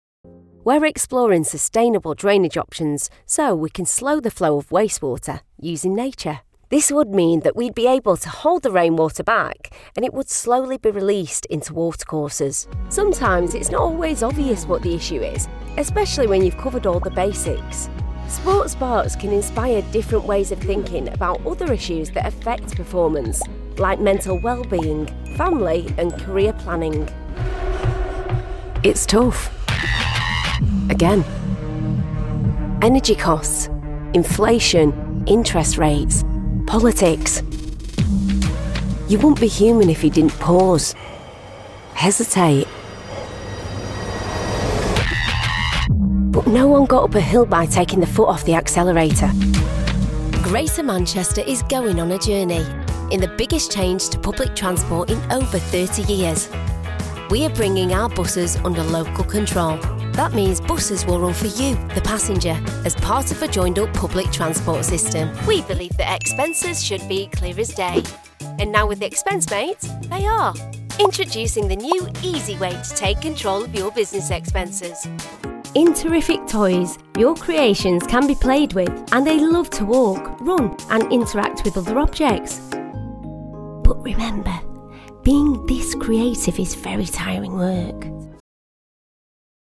Englisch (Britisch)
Kommerziell, Natürlich, Verspielt, Cool, Warm
Unternehmensvideo